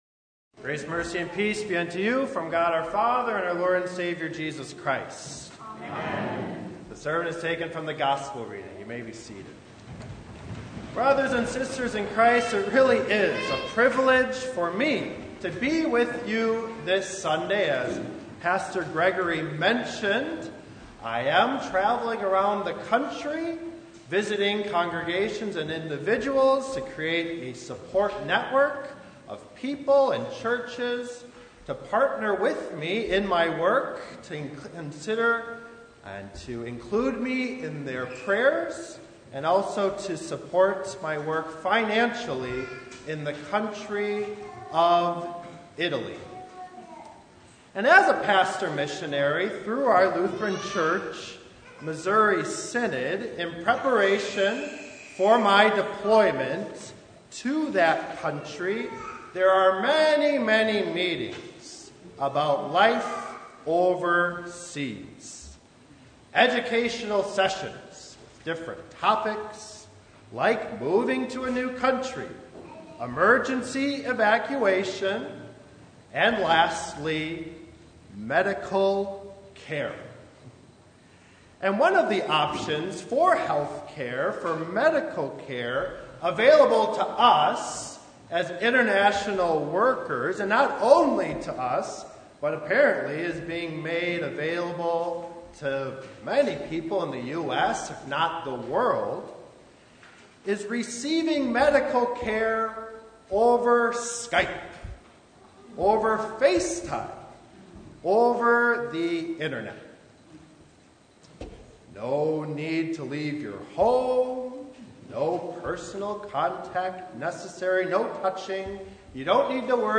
September 5, 2021 Up Close and Personal Preacher: Visiting Pastor Passage: Mark 7:31-37 God over skype?